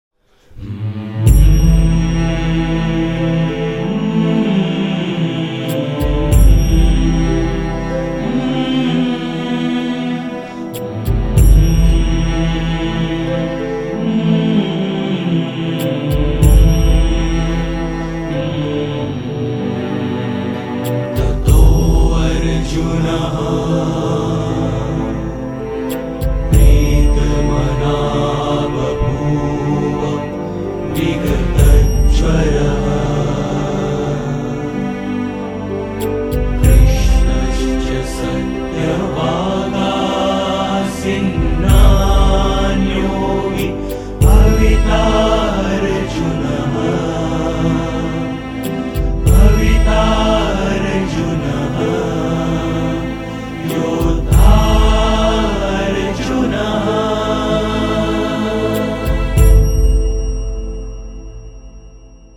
• Качество: 192, Stereo
саундтреки
спокойные
индийские
болливуд